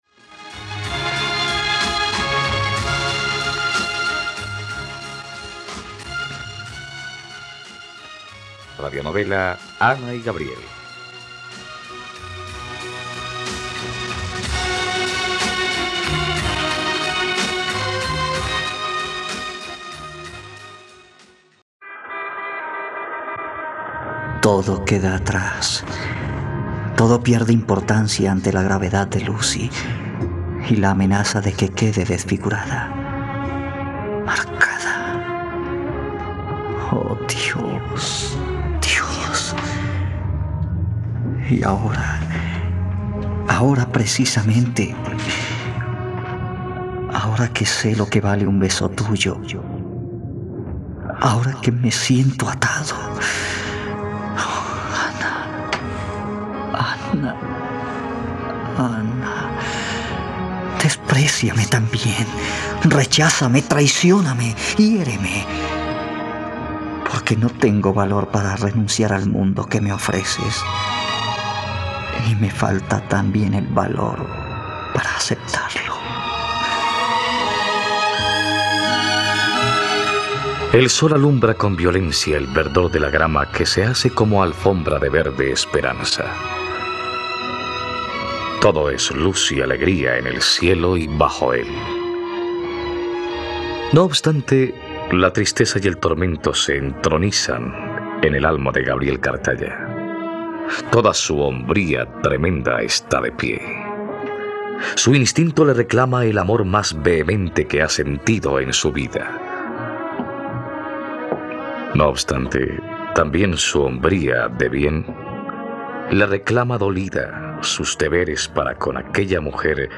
..Radionovela. Escucha ahora el capítulo 52 de la historia de amor de Ana y Gabriel en la plataforma de streaming de los colombianos: RTVCPlay.